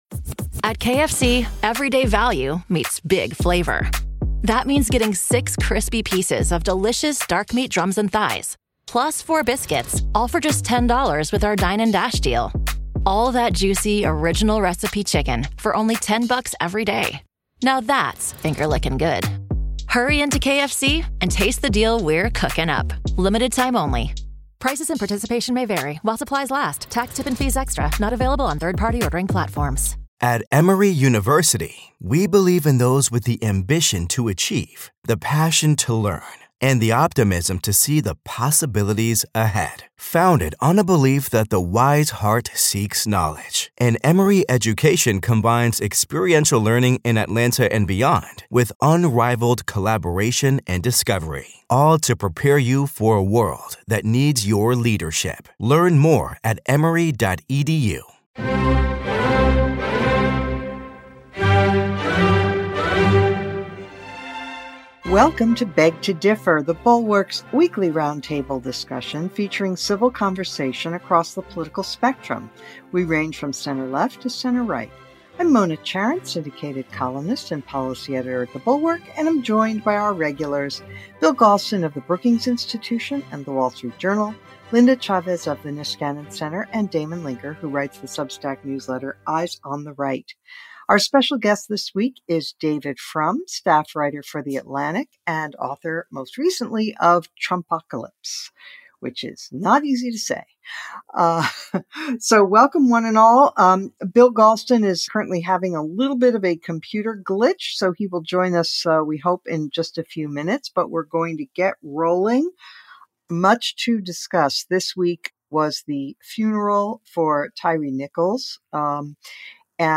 The Atlantic's David Frum joins the panel to discuss the coming GOP nomination fight, the Democrats' white liberal problem, and problems with policing.